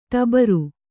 ♪ ṭabaru